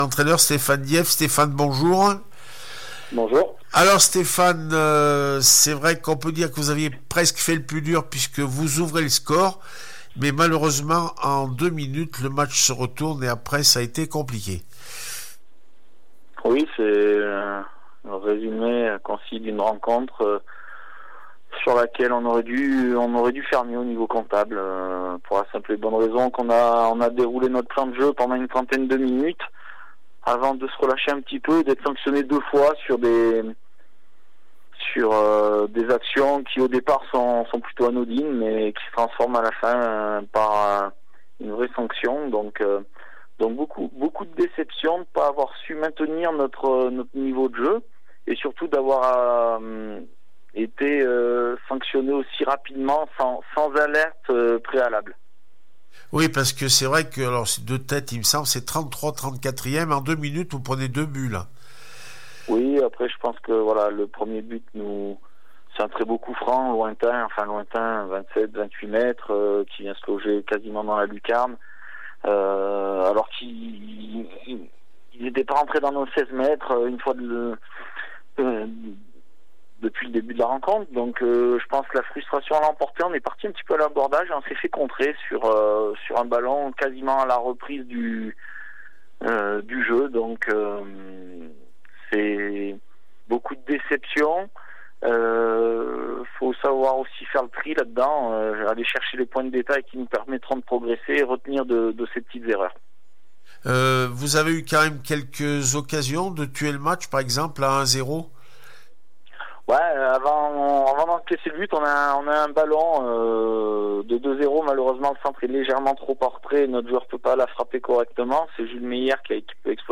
n2 foot Olympic Ales 2-1 le Puy foot 43 réaction après match